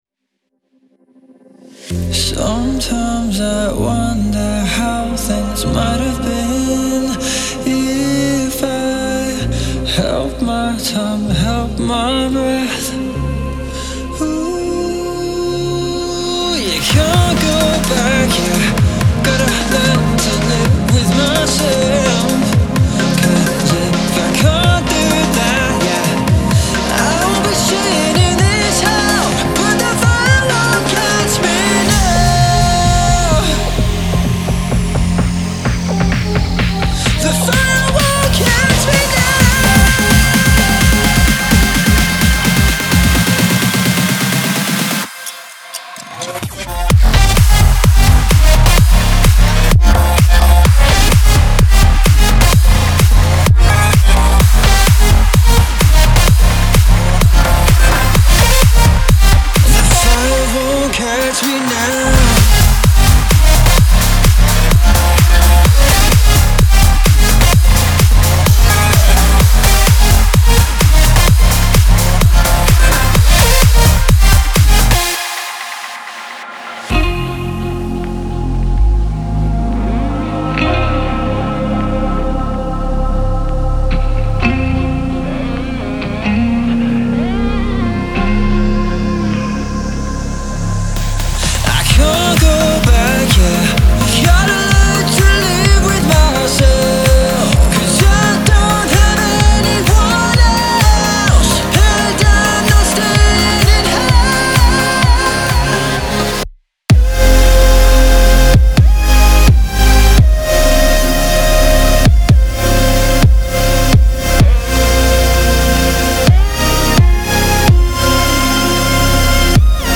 это энергичная трек в жанре EDM с элементами поп-музыки